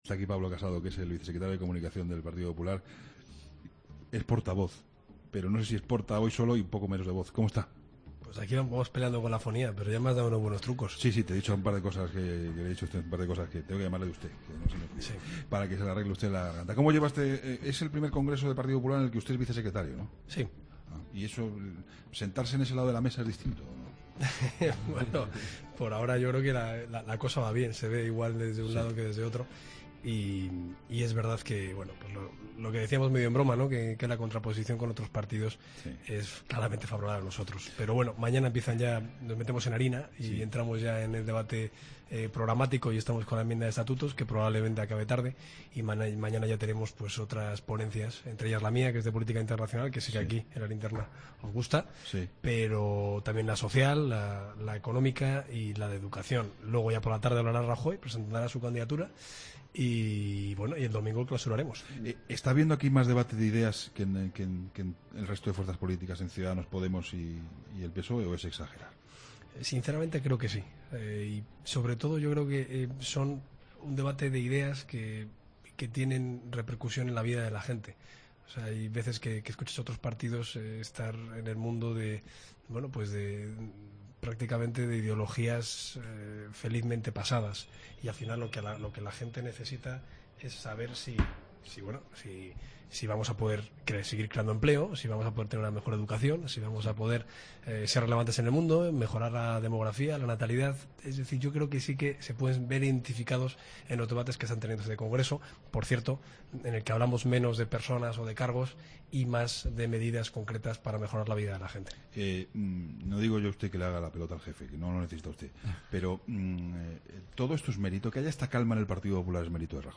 AUDIO: Pablo Casado, portavoz del PP, vicesecretario de Comunicación y coordinador de la Ponencia Europea en el Congreso del PP, en 'La Linterna'...